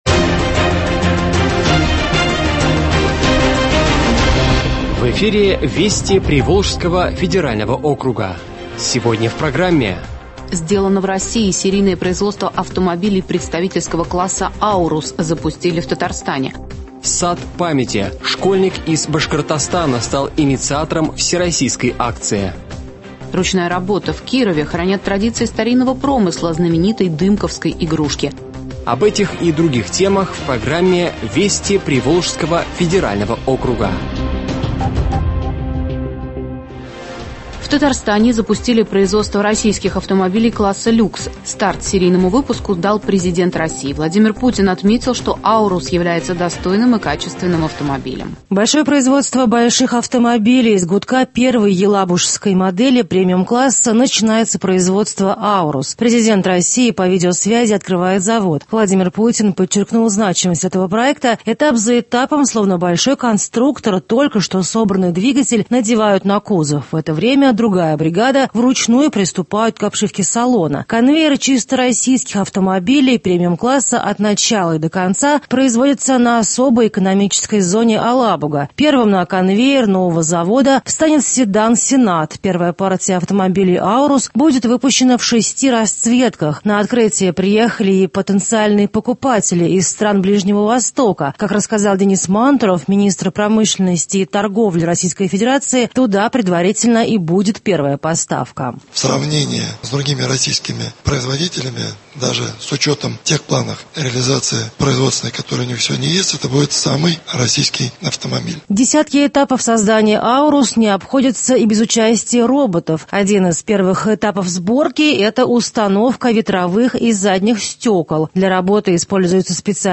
Радиообзор событий недели в регионах ПФО.